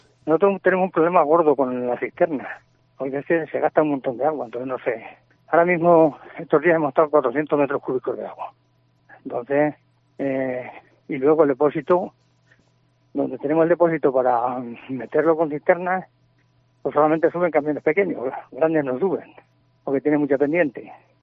Alcalde de Navalacruz. Mala accesibilidad depósito de agua